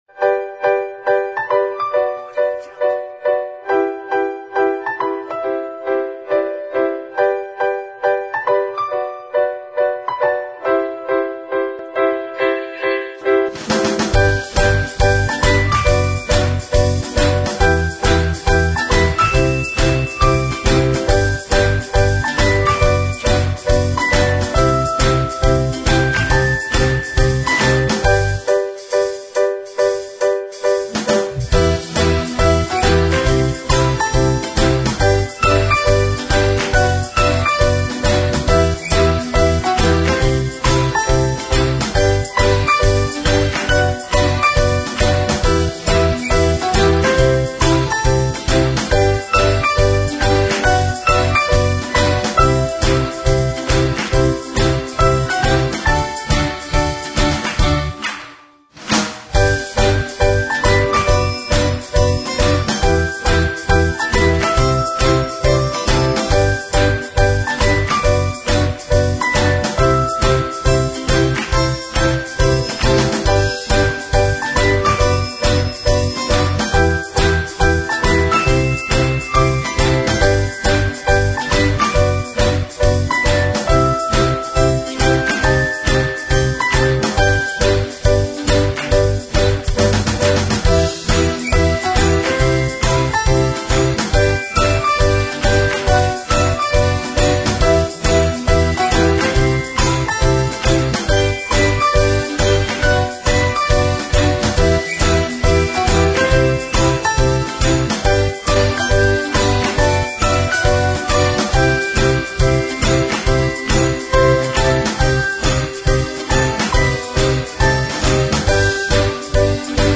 描述： 一首欢快、令人振奋和愉快的音乐曲目，内容包括有趣的钢琴部分、有趣的掌声、铃声、大键琴等。